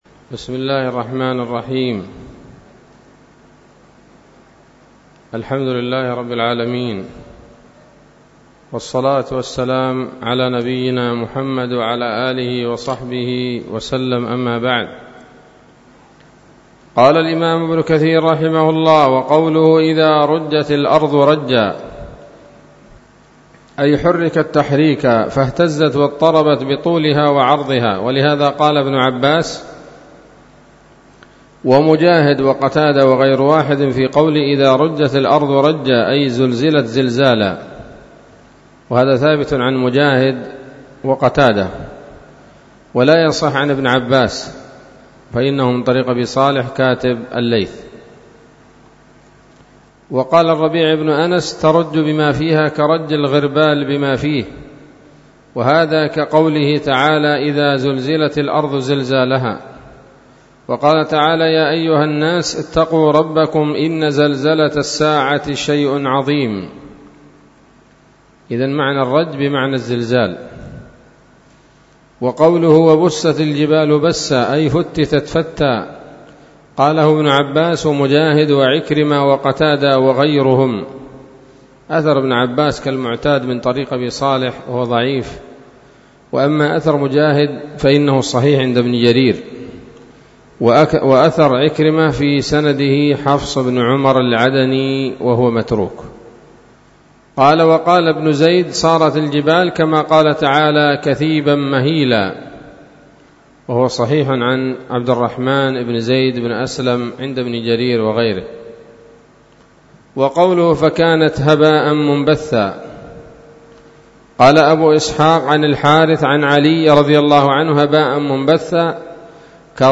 الدرس الثاني من سورة الواقعة من تفسير ابن كثير رحمه الله تعالى